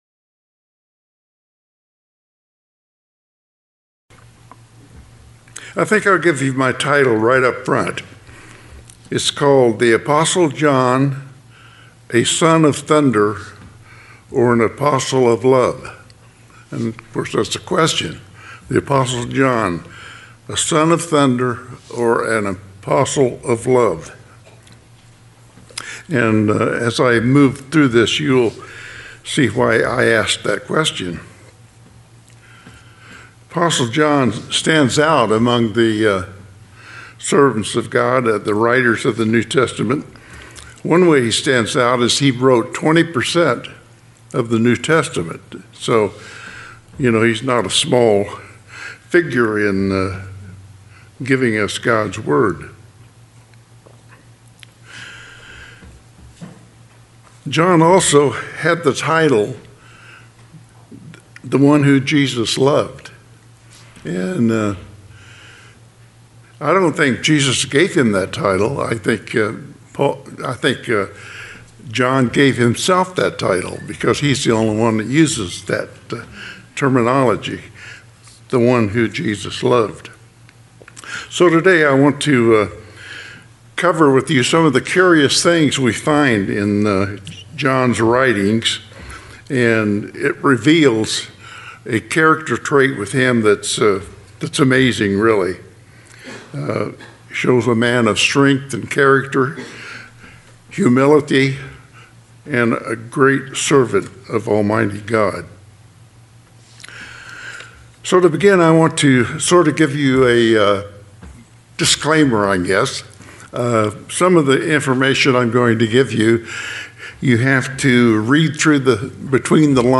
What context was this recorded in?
Given in Las Vegas, NV Redlands, CA San Diego, CA